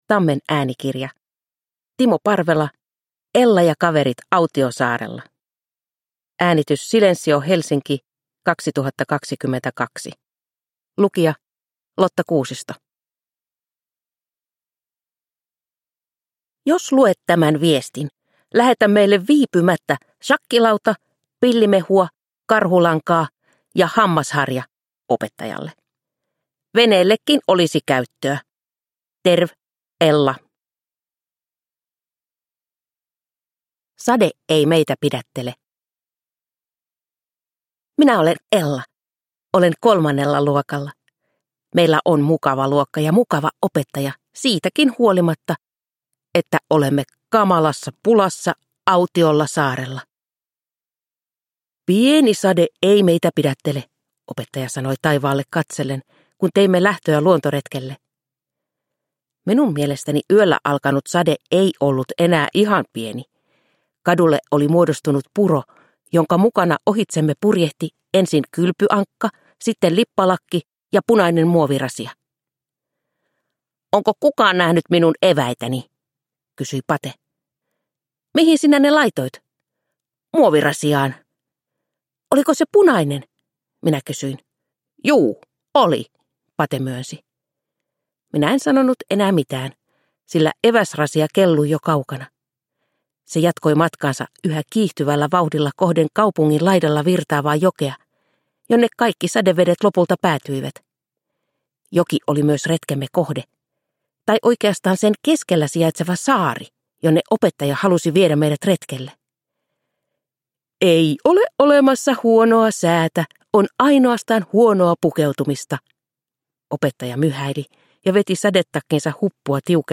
Ella ja kaverit autiosaarella – Ljudbok – Laddas ner